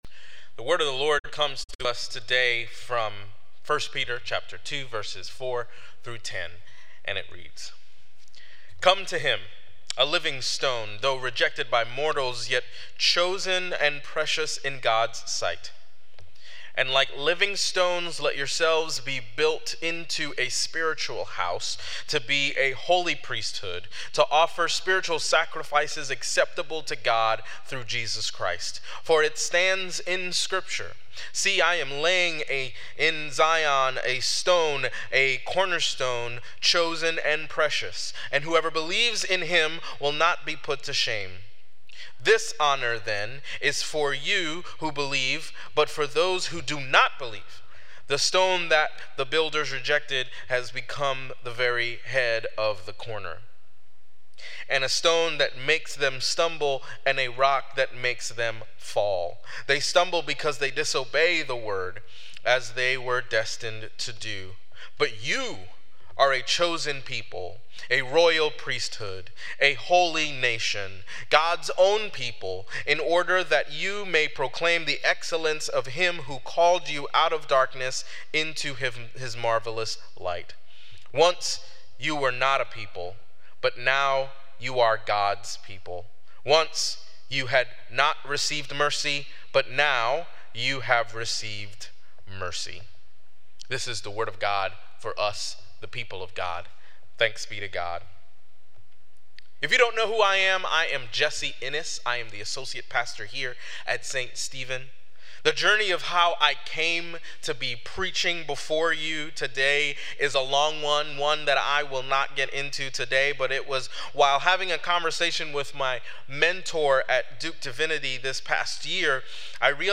Sermon Reflections: How does the metaphor of the church as a spiritual house resonate with you?